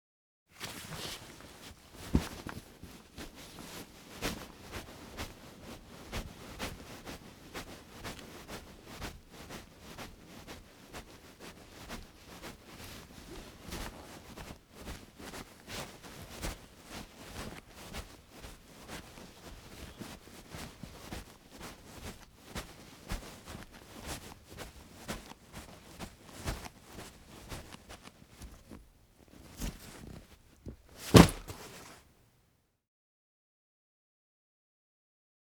Hockey Equipment Bag Pick Up Sound
household